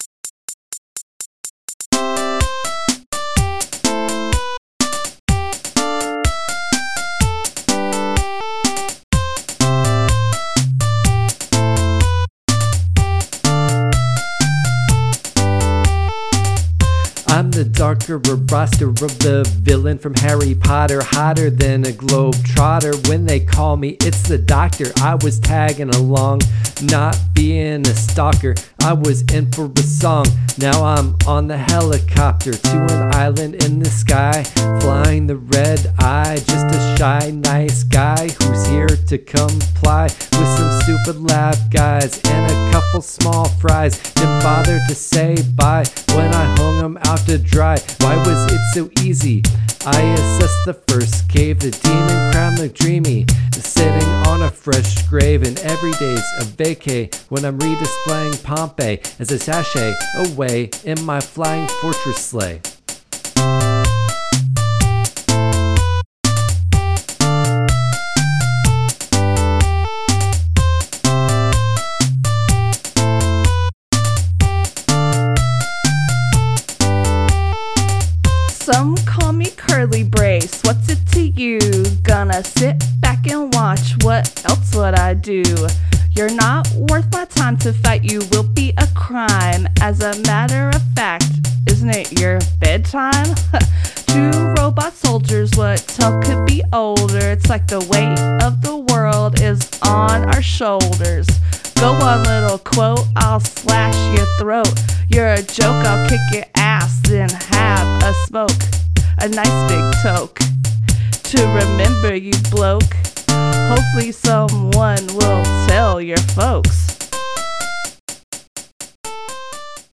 Rap from Episode 29: Cave Story – Press any Button
Cave-Story-Rap.mp3